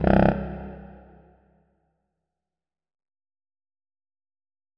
4aef571f59 Divergent / mods / Hideout Furniture / gamedata / sounds / interface / keyboard / saxophone / notes-00.ogg 38 KiB (Stored with Git LFS) Raw History